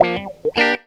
GTR 87 GM.wav